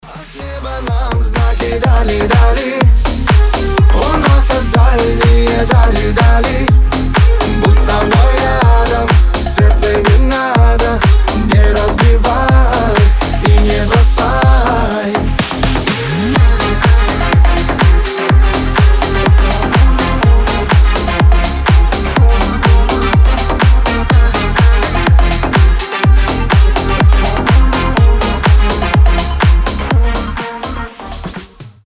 • Качество: 320, Stereo
громкие
Club House
ремиксы